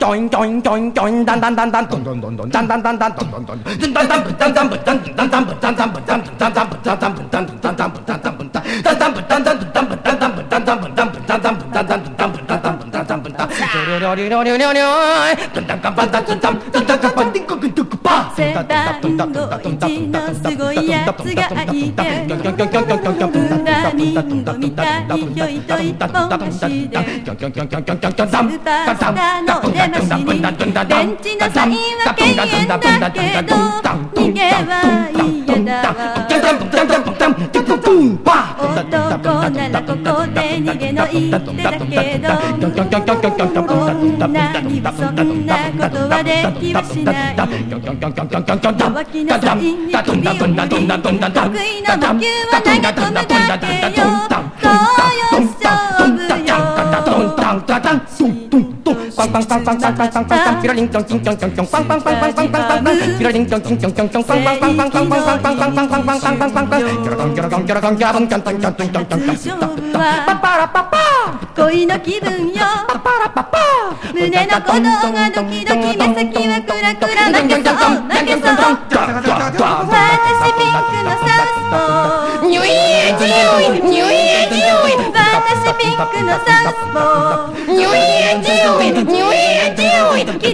DRUM BREAK
最高の和モノ・ドラム・レコード！
ひたすらドラム・フレーズのみ続くものから、メロウ/ファンキーなバンド・インストまで収録！